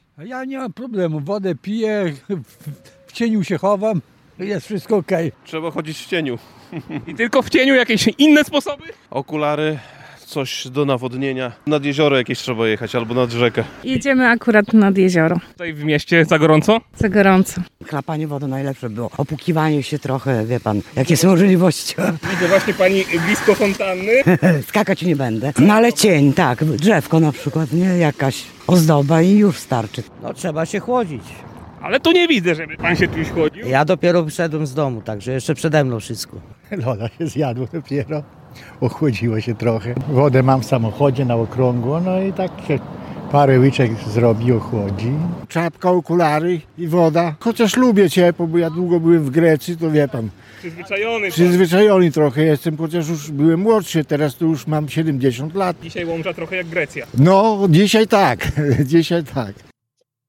Łomża: Sonda: Jak mieszkańcy radzą sobie z upałami?
– Łomża przypomina w czwartek (3.07) Grecję – usłyszał nasz reporter na ulicach miasta. W związku z rekordowymi upałami zapytaliśmy mieszkańców o to, jakie są ich sprawdzone sposoby na walkę z lejącym się z nieba żarem.